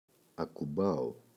ακουμπάω [aku’mbao]